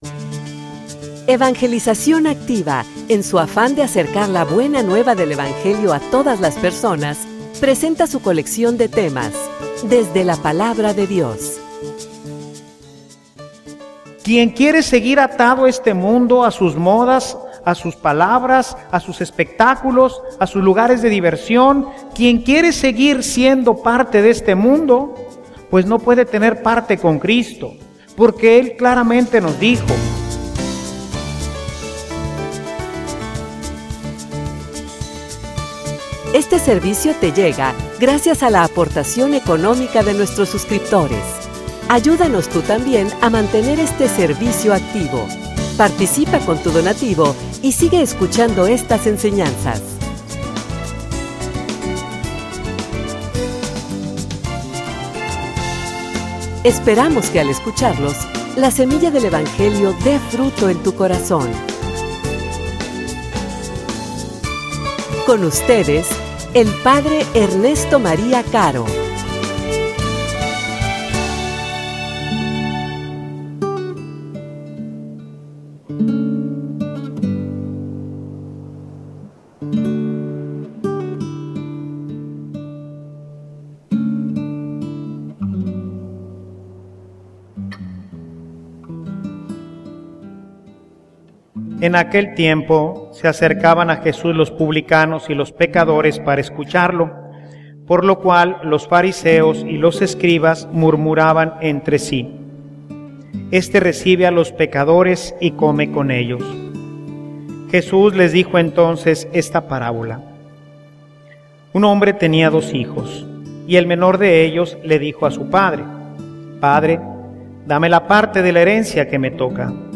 homilia_Un_sacramento_en_crisis.mp3